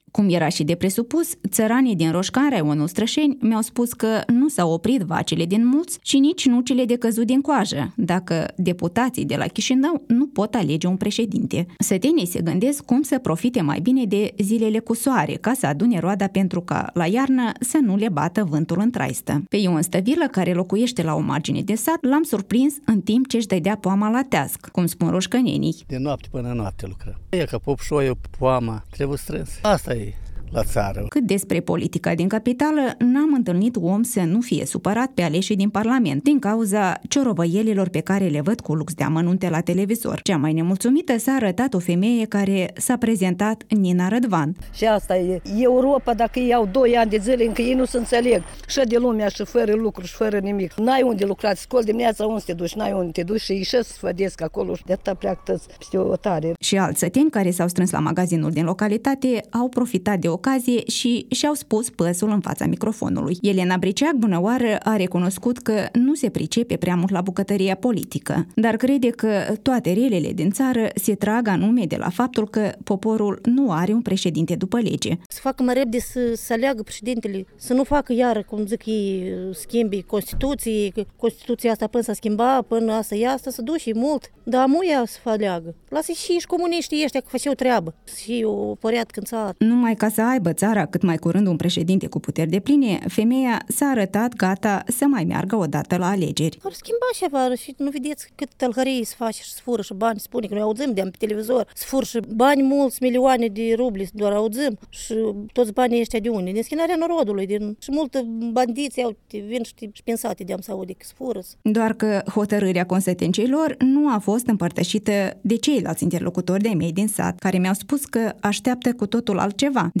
Şi alţi săteni care s-au strîns la magazinul din localitate au profitat de ocazie şi şi-au spus păsul în faţa microfonului.